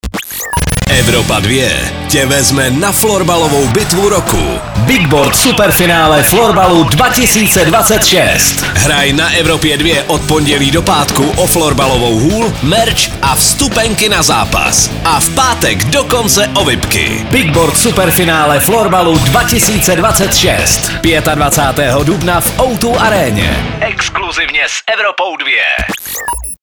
liner_superfinal_florbal_soutez_2026.mp3